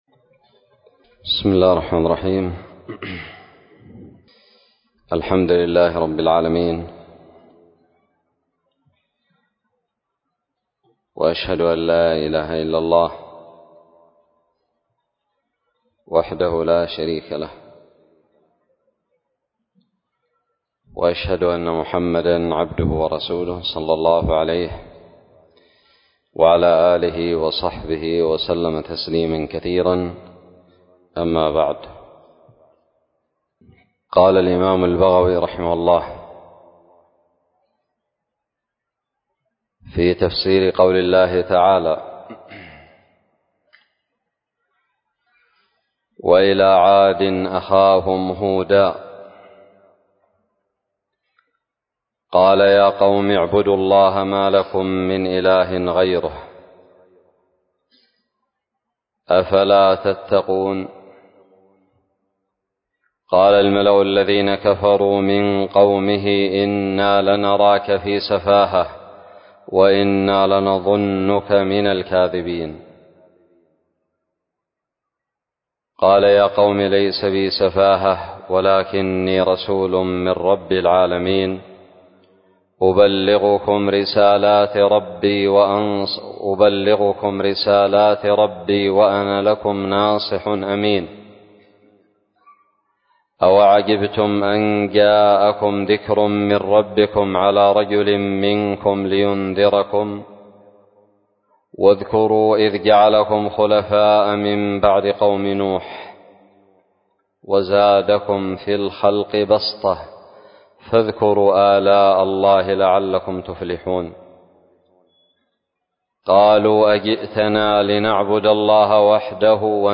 الدرس السابع عشر من تفسير سورة الأعراف من تفسير البغوي
ألقيت بدار الحديث السلفية للعلوم الشرعية بالضالع